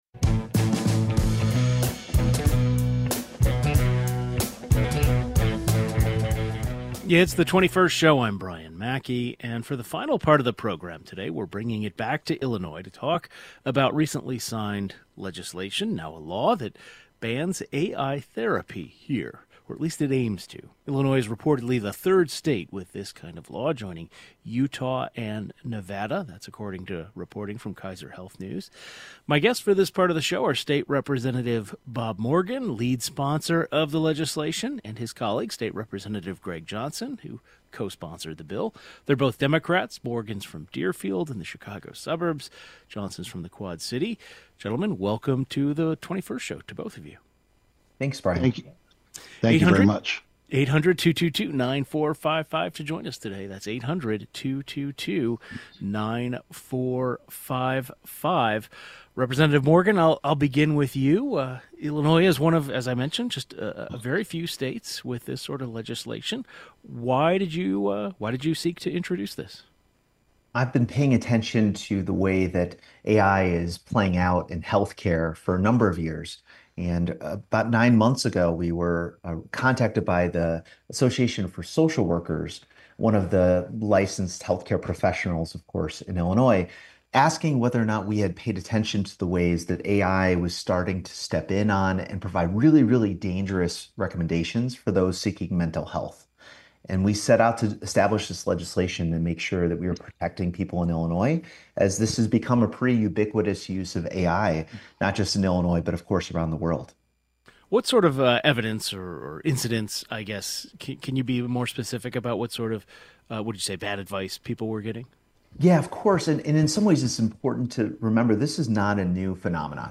Both lawmakers join the program to discuss the dangers of using AI for treating patients with mental health issues.